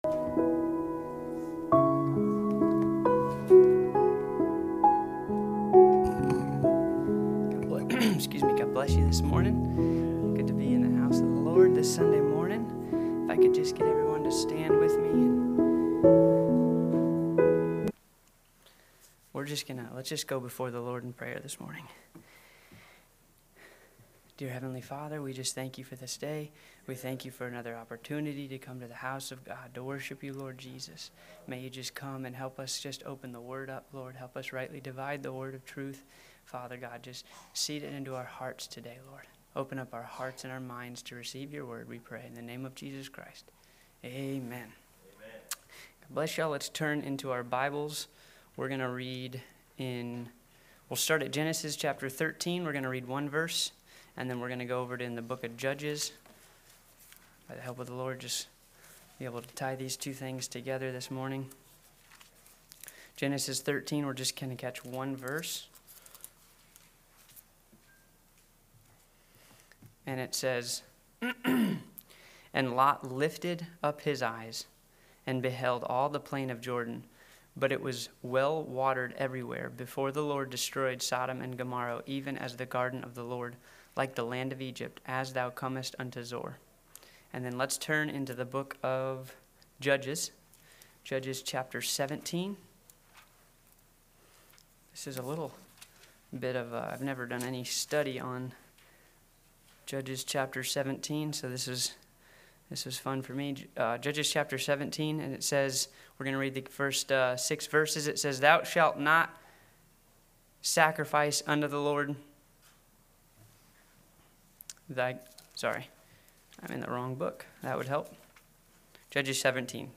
(Sunday School) Everlasting Consequences